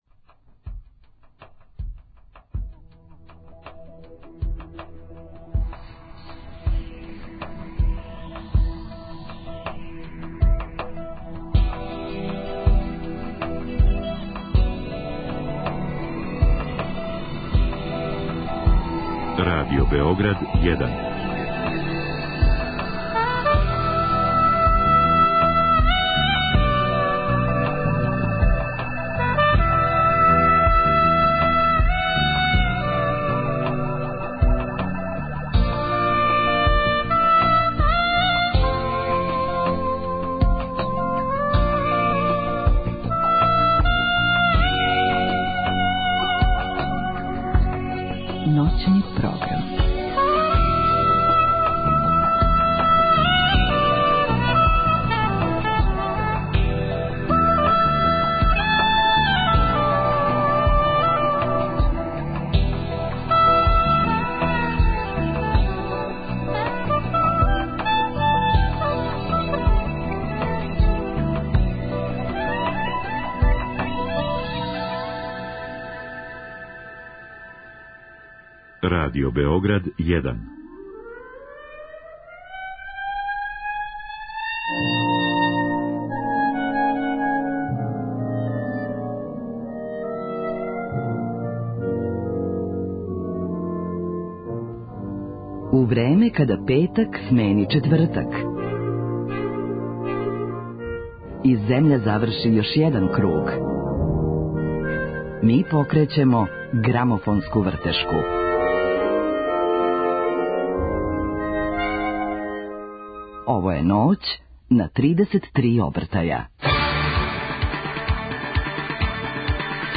У овој ноћи ћемо слушати домаће плоче.